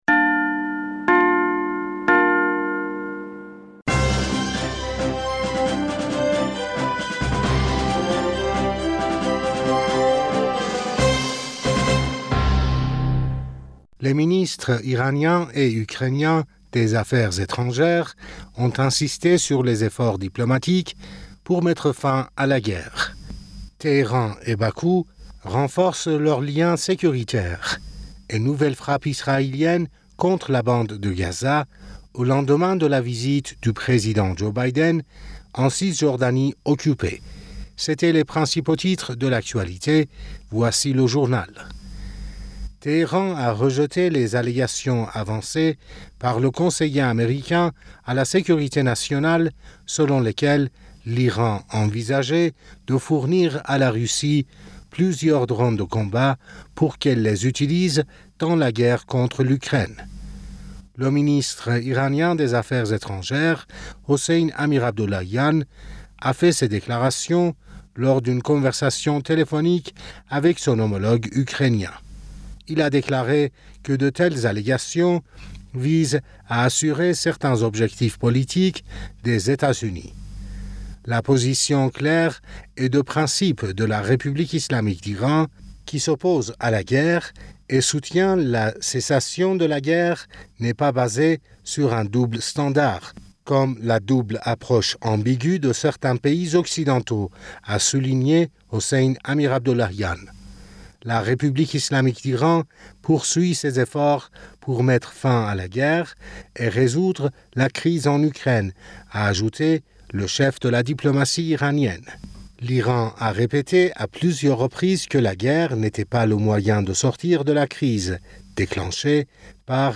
Bulletin d'information Du 16 Julliet